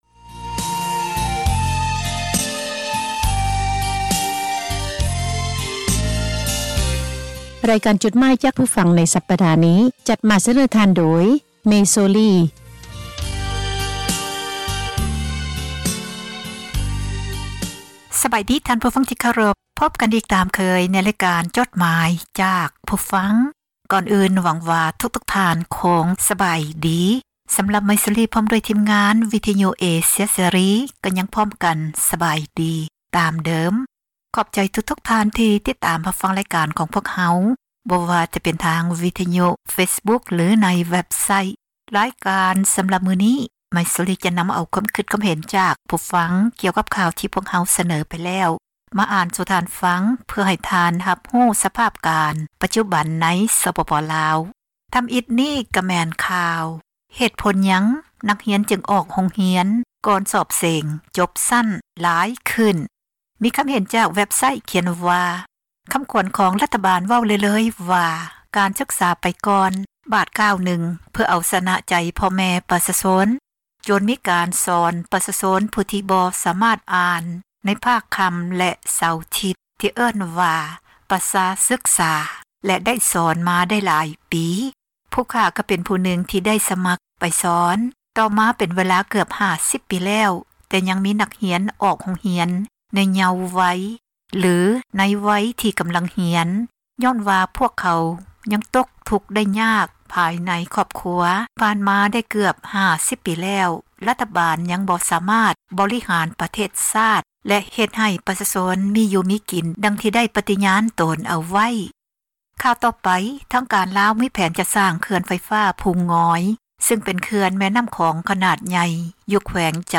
( ເຊີນທ່ານ ຟັງຣາຍລະອຽດ ຈາກສຽງບັນທຶກໄວ້) ໝາຍເຫດ: ຄວາມຄິດຄວາມເຫັນ ຂອງຜູ່ອ່ານ ທີ່ສະແດງອອກ ໃນເວັບໄຊທ໌ ແລະ ເຟສບຸກຄ໌ ຂອງວິທຍຸ ເອເຊັຽ ເສຣີ, ພວກເຮົາ ທິມງານ ວິທຍຸເອເຊັຽເສຣີ ໃຫ້ຄວາມສຳຄັນ ແລະ ຂອບໃຈ ນຳທຸກໆຖ້ອຍຄຳ, ແລະ ມີໜ້າທີ່ ນຳມາອ່ານໃຫ້ທ່ານ ໄດ້ຮັບຟັງກັນ ແລະ ບໍ່ໄດ້ເສກສັນປັ້ນແຕ່ງໃດໆ, ມີພຽງແຕ່ ປ່ຽນຄຳສັພ ທີ່ບໍ່ສຸພາບ ໃຫ້ເບົາລົງ ເທົ່ານັ້ນ. ດັ່ງນັ້ນ ຂໍໃຫ້ທ່ານຜູ່ຟັງ ຈົ່ງຕັດສິນໃຈເອົາເອງ ວ່າ ຄວາມຄິດເຫັນນັ້ນ ເປັນໜ້າເຊື່ອຖື ແລະ ຄວາມຈິງ ຫລາຍ-ໜ້ອຍ ປານໃດ.